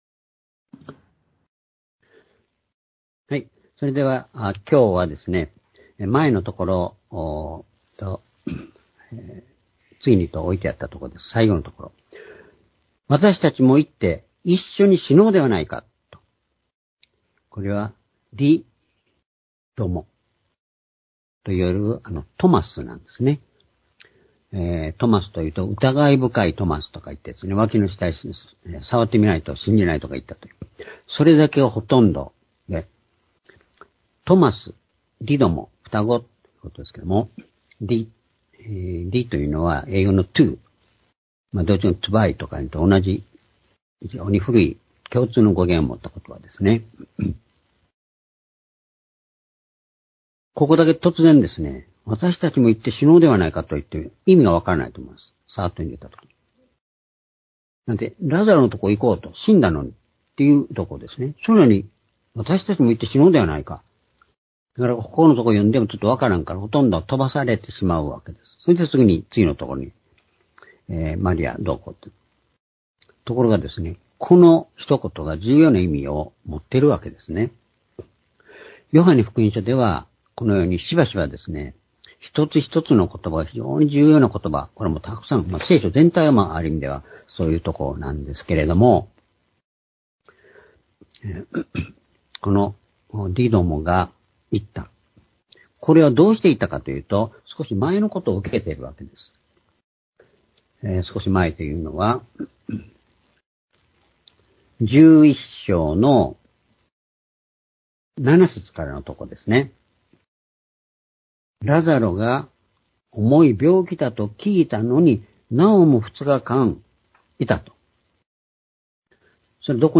主日礼拝日時 ２０２３年8月6日（主日礼拝) 聖書講話箇所 「死と生」 ヨハネ11の16-22 ※視聴できない場合は をクリックしてください。